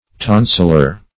Search Result for " tonsillar" : The Collaborative International Dictionary of English v.0.48: Tonsilar \Ton"sil*ar\, a. (Anat.) Of or pertaining to the tonsils; tonsilitic.